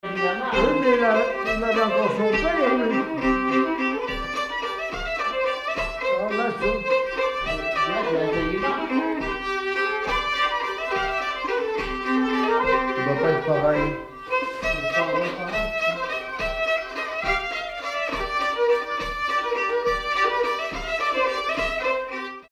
Polka
danse : polka
circonstance : bal, dancerie
Pièce musicale inédite